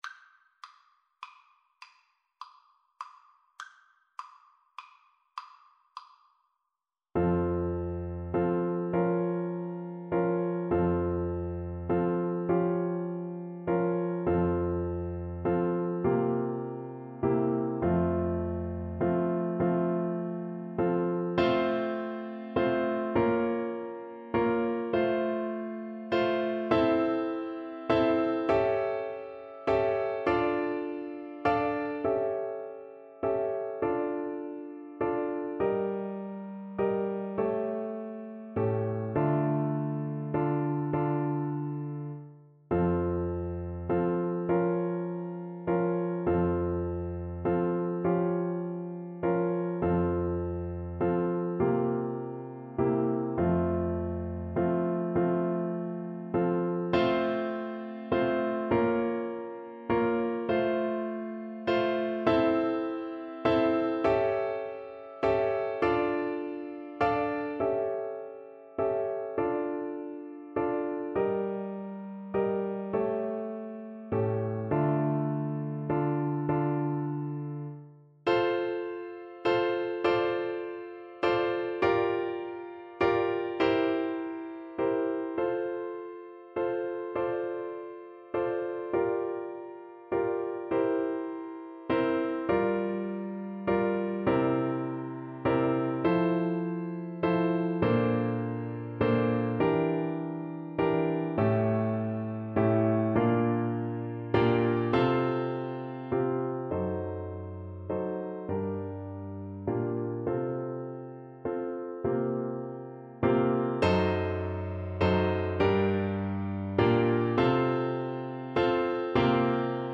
Classical Paradis, Maria Theresia von Sicilienne Flute version
Play (or use space bar on your keyboard) Pause Music Playalong - Piano Accompaniment Playalong Band Accompaniment not yet available transpose reset tempo print settings full screen
6/8 (View more 6/8 Music)
F major (Sounding Pitch) (View more F major Music for Flute )
Classical (View more Classical Flute Music)